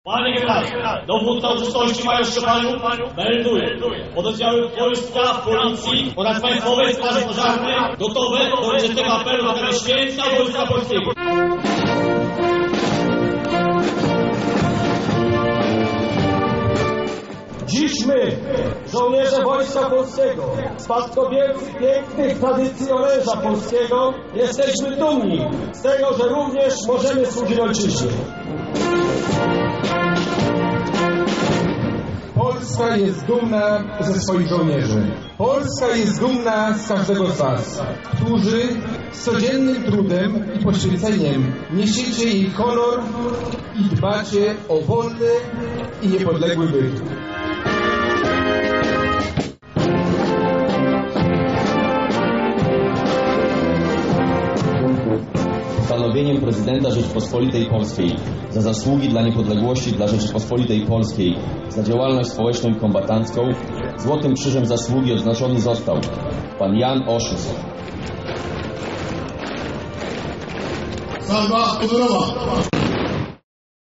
Lubelscy mundurowi także świętowali. Obchody Święta Wojska Polskiego tym razem odbyły się pod Zamkiem Lubelskim
swieto-wojska-polskiego.mp3